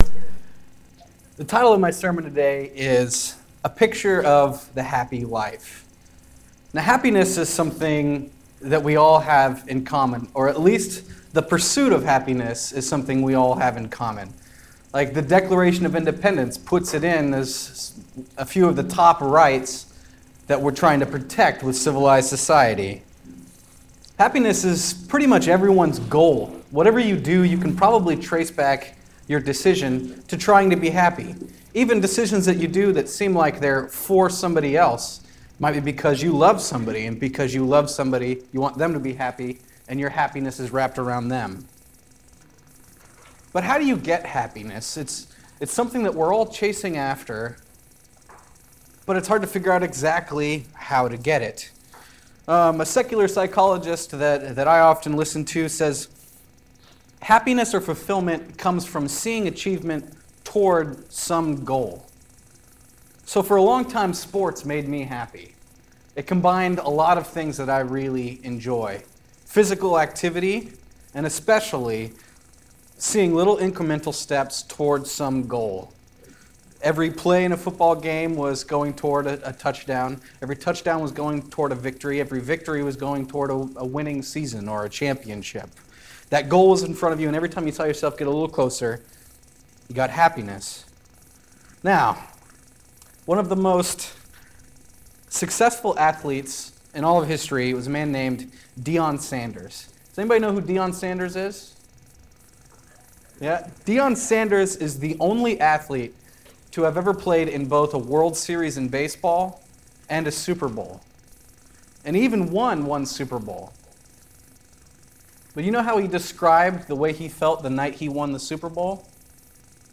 Sermon August 27, 2017